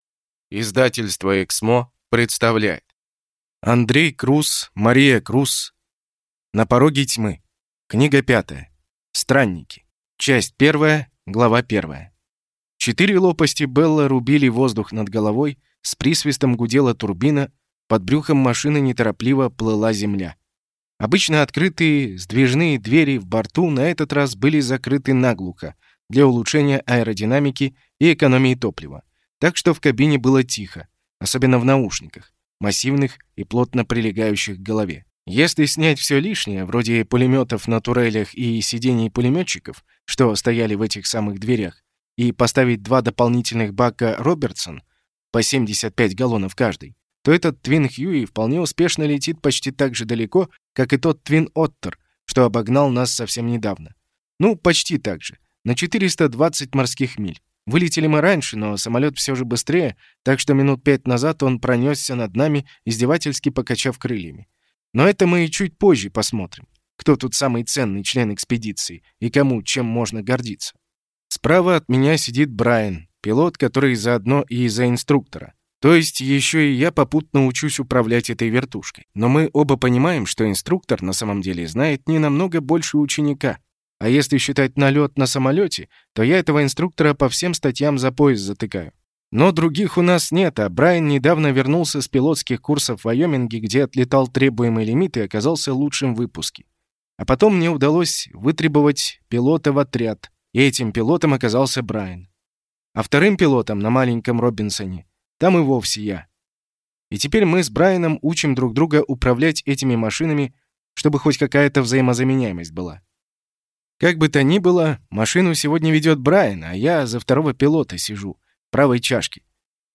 Аудиокнига Странники | Библиотека аудиокниг